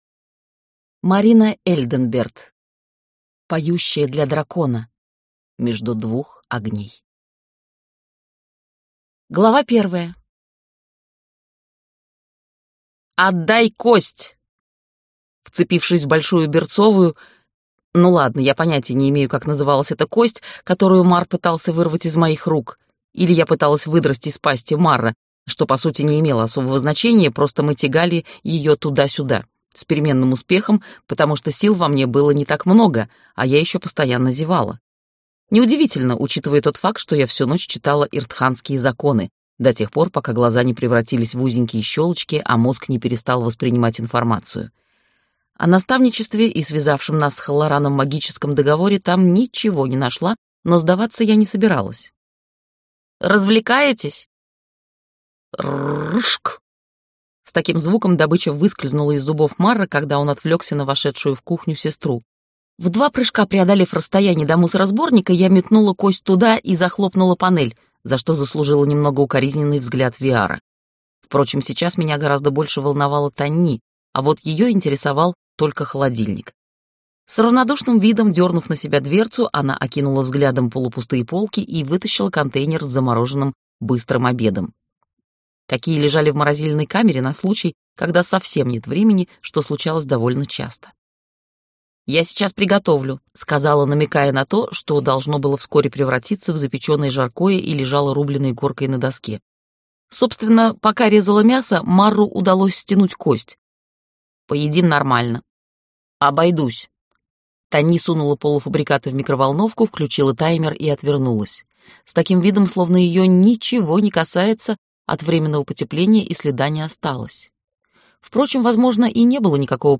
Аудиокнига Поющая для дракона.